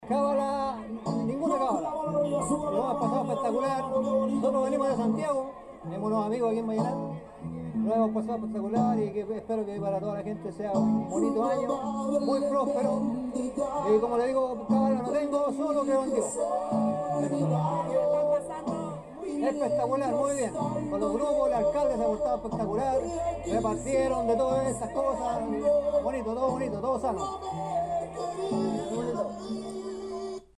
Sin embargo el rivereño recibió a mas de 4.000 personas quienes llegaron al lugar para poder disfrutar de esta fiesta de fin de año, donde la mayoría disfruto en familia en una noche llena de alegría, ritmo y unión , así lo evidenciaron las siguientes impresiones.